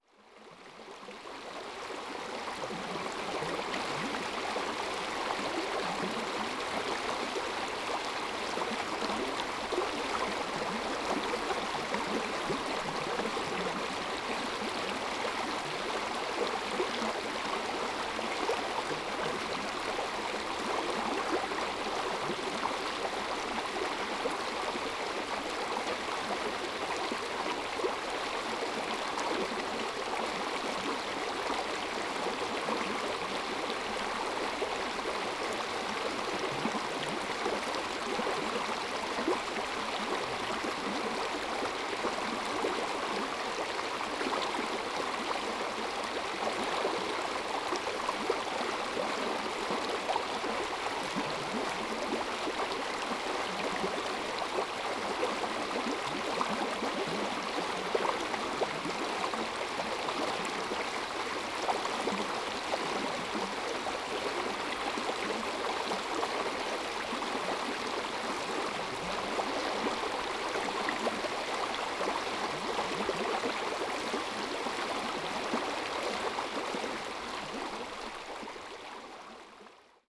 Recordings from the trail through the beautiful old growth forest at Fillongley Provincial Park on Denman Island with the bird- and creek-sounds in early spring 2022.
4. Creek sounds – Little George Creek